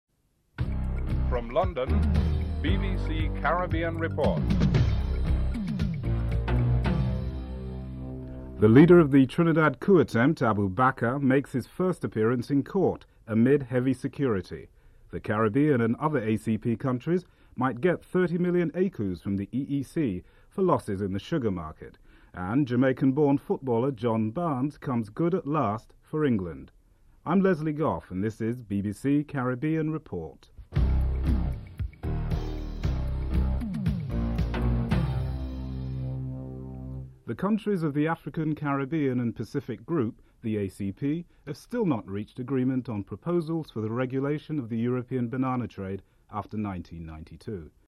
The British Broadcasting Corporation
1. Headlines (00:00-00:36)
4. Financial News (05:52-06:40)